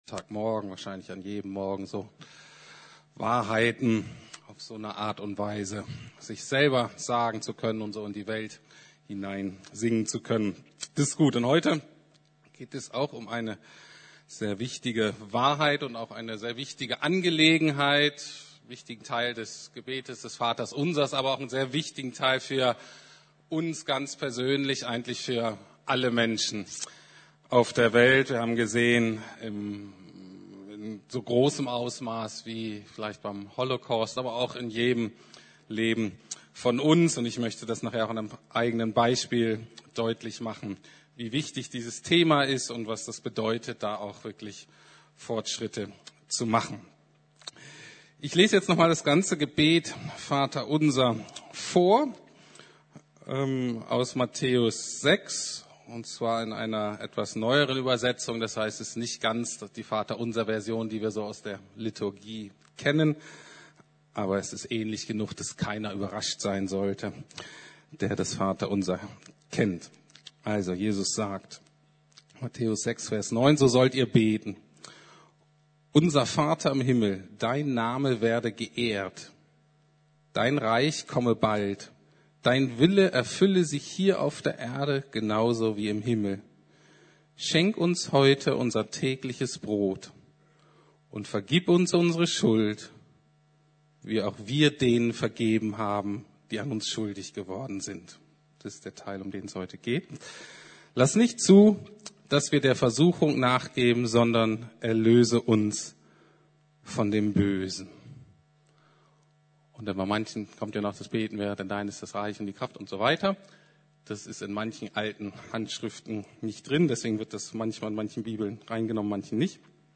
Vergib unsere Schuld, wie auch wir vergeben unseren Schuldigern ~ Predigten der LUKAS GEMEINDE Podcast